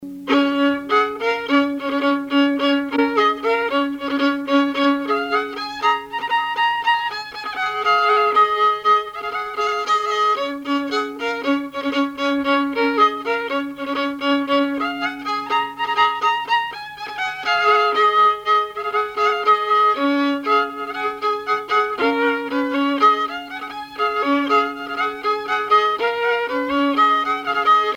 Polka au violon
musique traditionnelle ; violoneux, violon,
danse : polka
Pièce musicale inédite